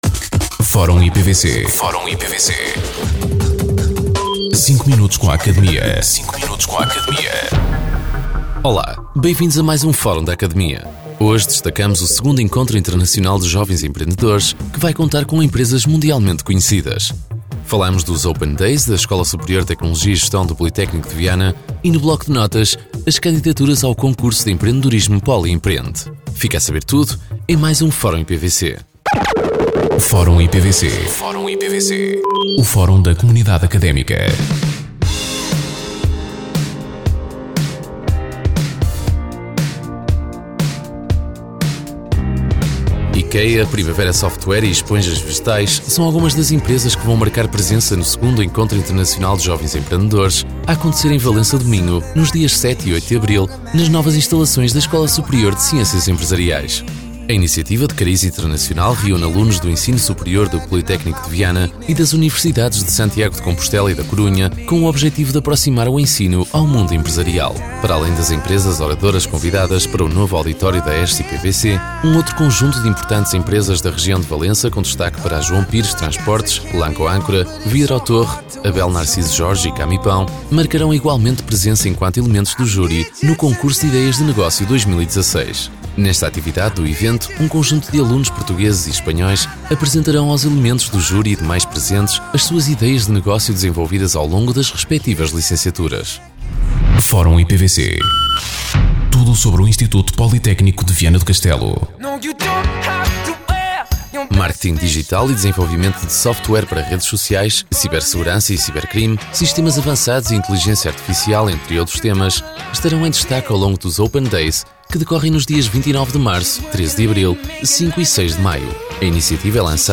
O Instituto Politécnico de Viana do Castelo [IPVC] tem mais um espaço radiofónico a ser transmitido, desta feita, na Rádio Caminha [RJC FM], em 106.2.